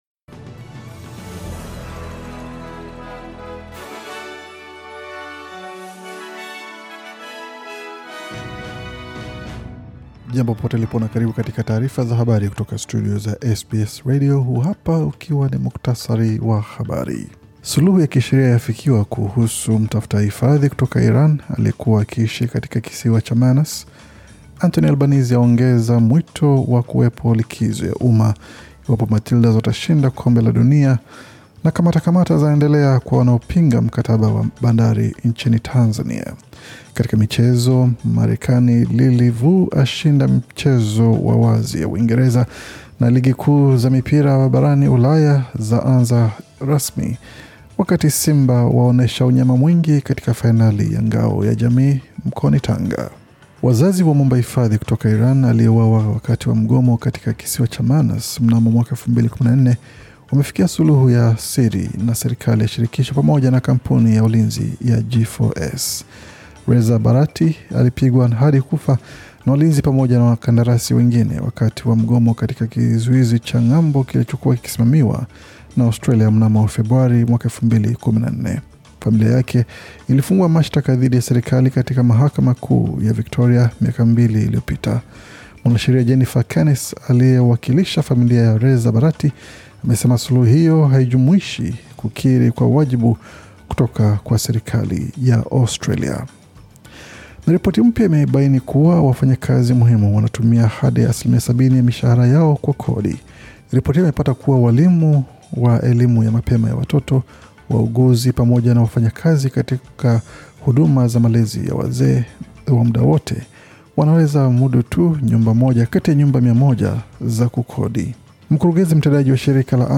Taarifa ya Habari 14 Agosti 2023